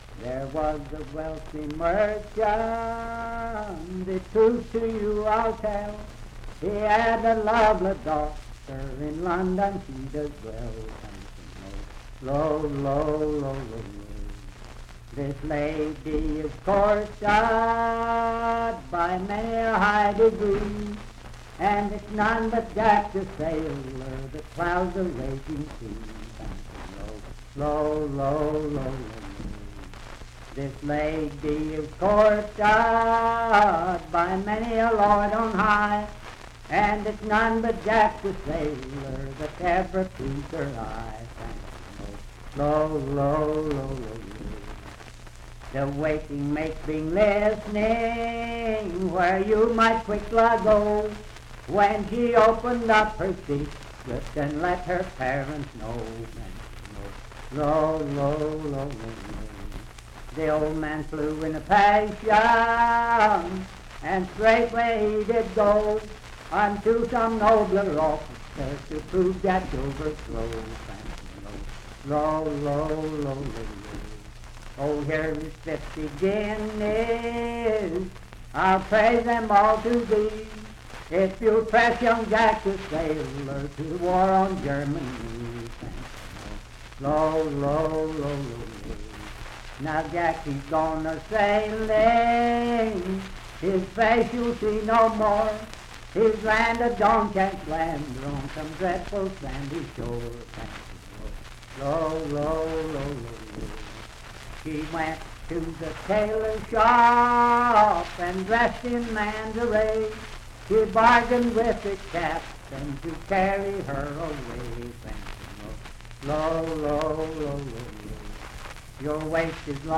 Unaccompanied vocal music and folktales
Voice (sung)
Parkersburg (W. Va.), Wood County (W. Va.)